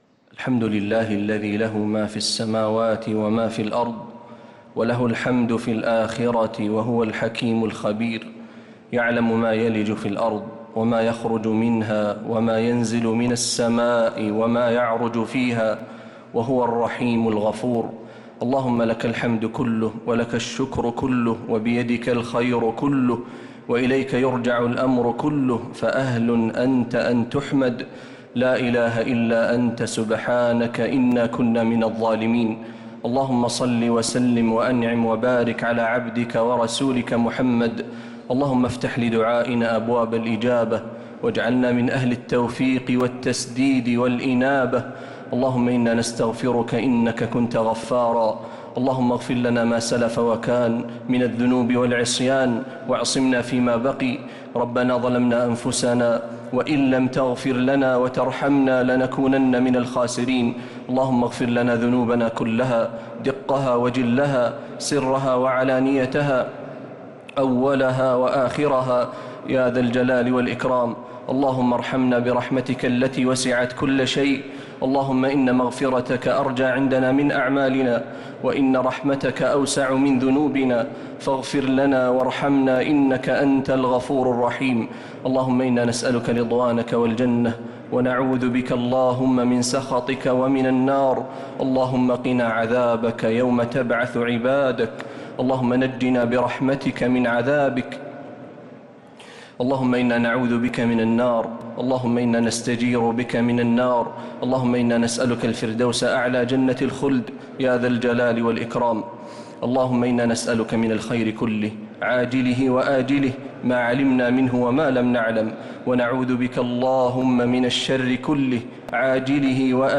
دعاء القنوت ليلة 7 رمضان 1446هـ | Dua 7th night Ramadan 1446H > تراويح الحرم النبوي عام 1446 🕌 > التراويح - تلاوات الحرمين